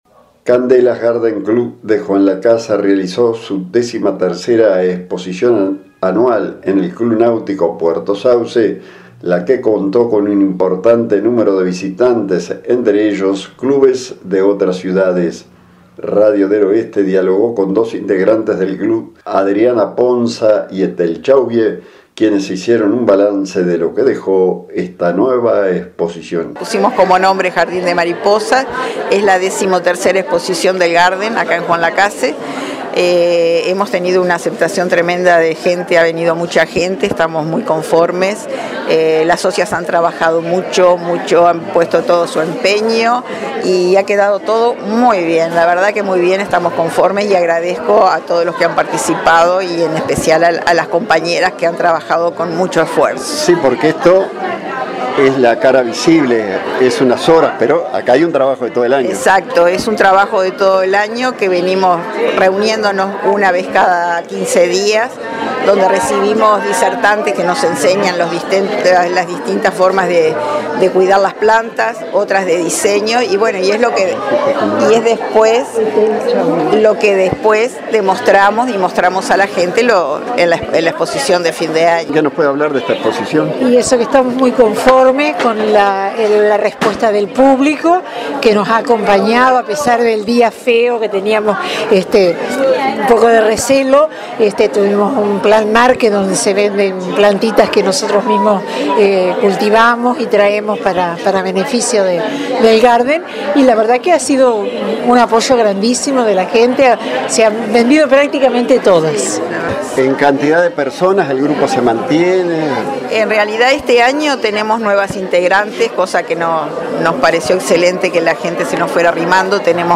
Radio del Oeste dialogó con dos integrantes de Candelas Garden